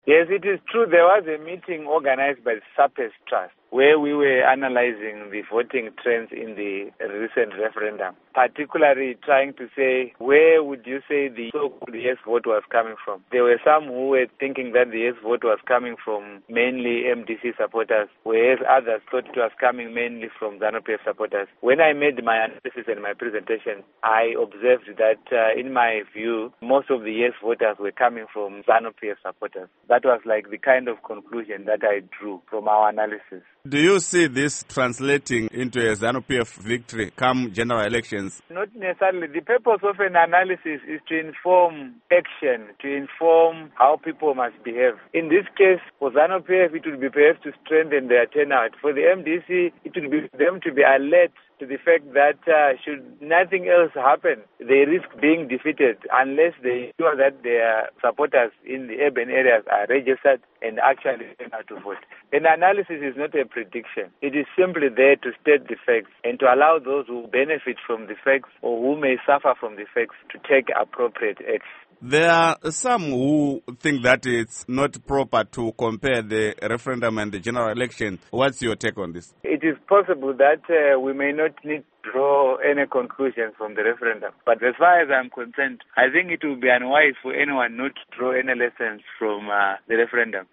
Interview With Lovemore Madhuku